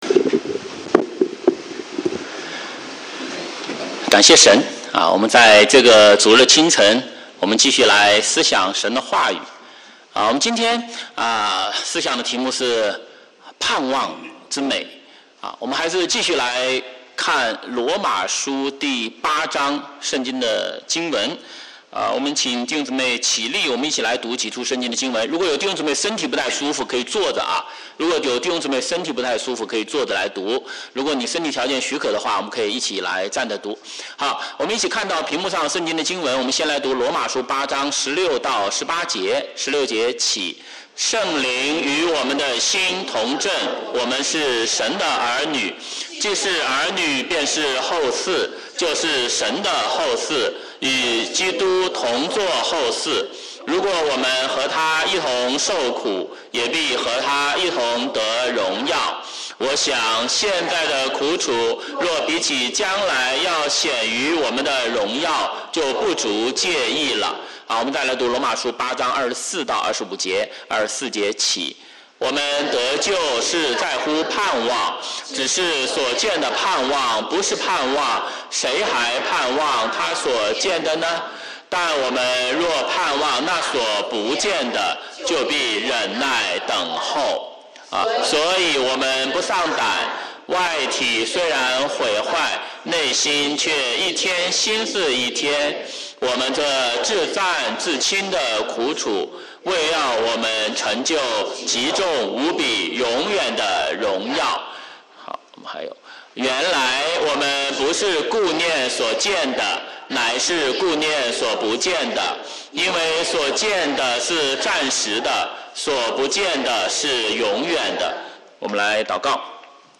華語主日崇拜講道錄音